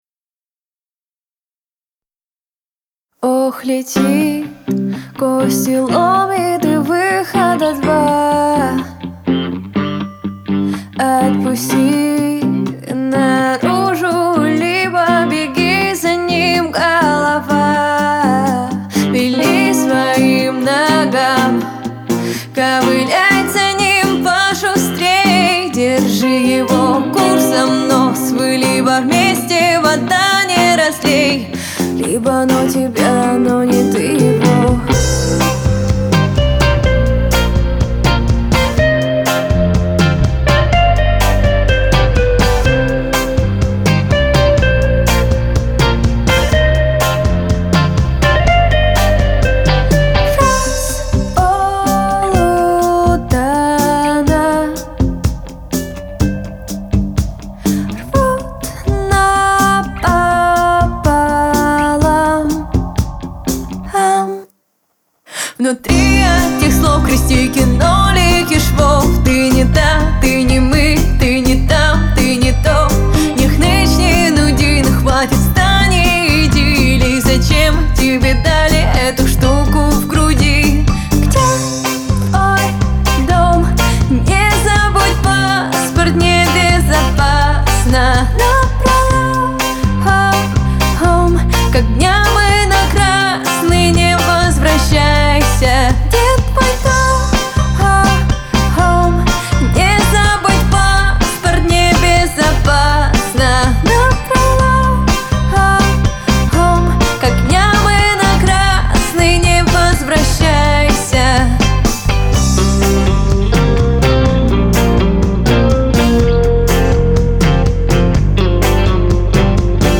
Я применил вот такой эквалайзер, прямо к мастеру.